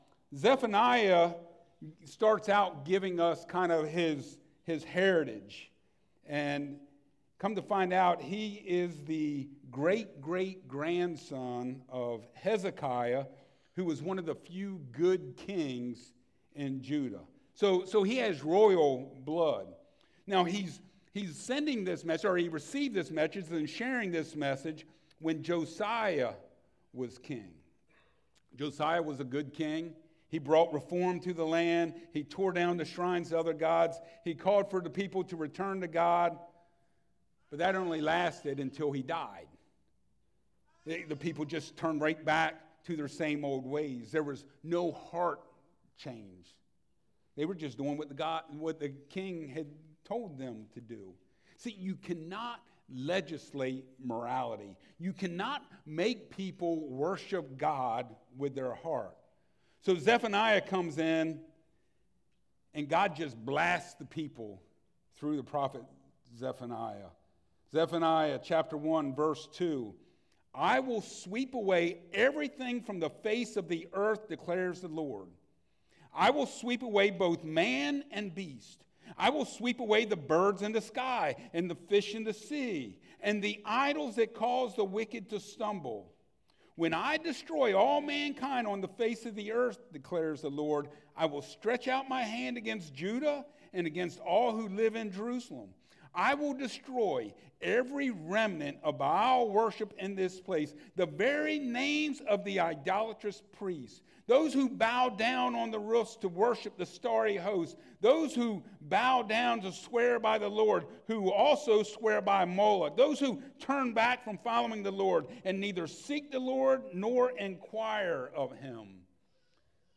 Service Type: Sunday Mornings